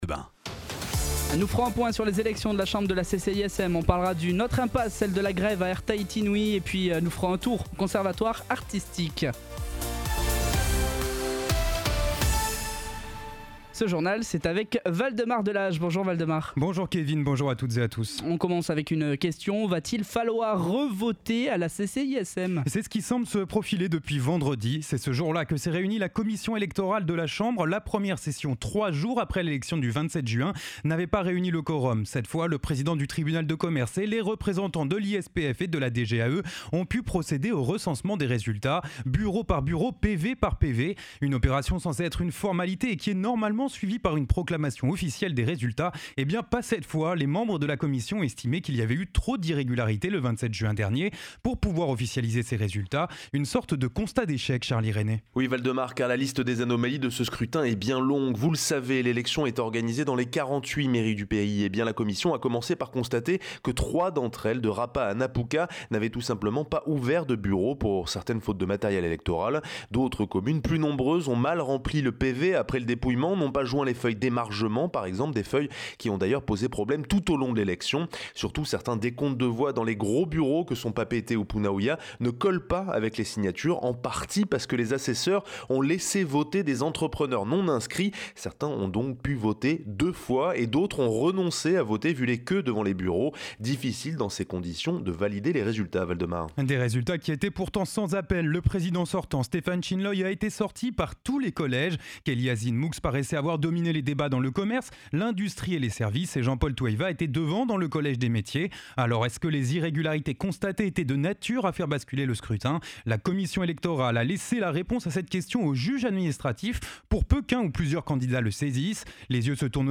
Journal de 12h, le 10/07/2023